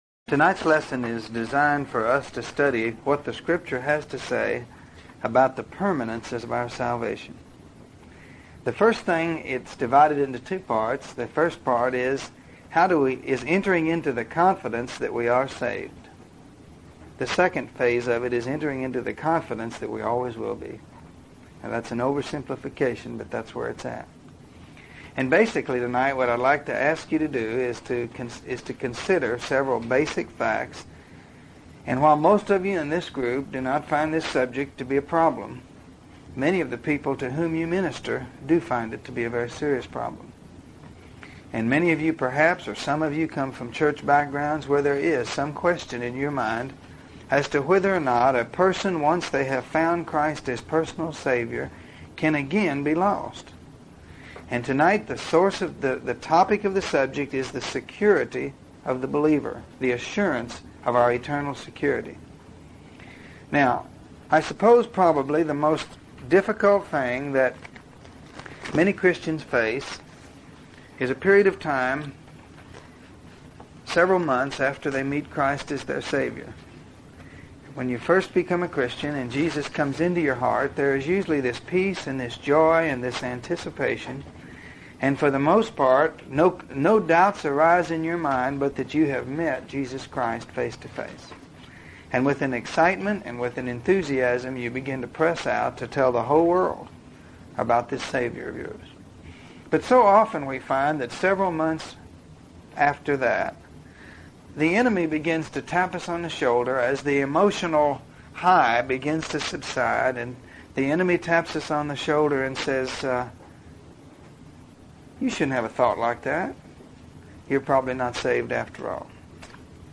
If you are having trouble with being assured of your salvation, this lesson will give you both an understanding of what it means to be saved, and the confidence of knowing that once you are saved, it is for all of eternity. Belief in God is not a matter of feelings, but a matter of the will.